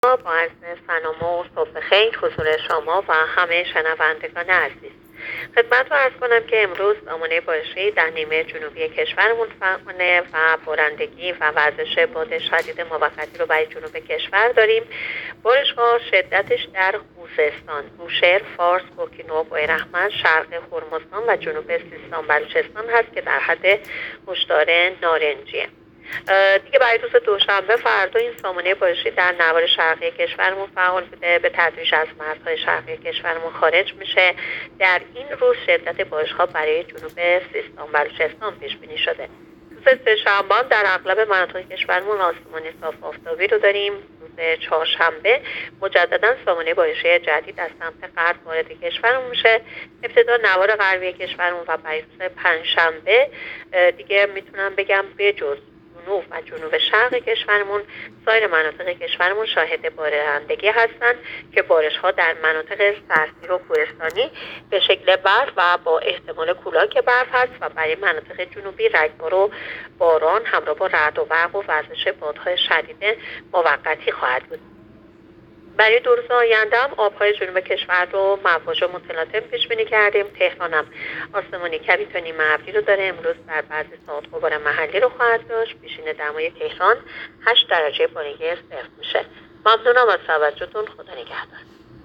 گزارش رادیو اینترنتی از آخرین وضعیت آب و هوای پنجم بهمن؛